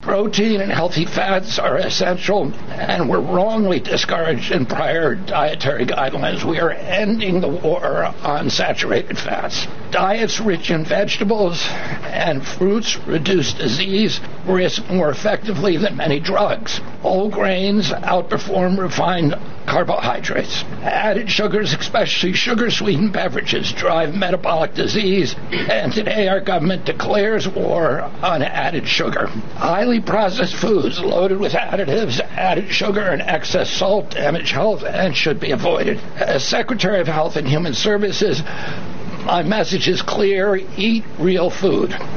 Audio with Robert Kennedy, Jr., Secretary of the Department of Health and Human Services, and Ag Secretary Brooke Rollins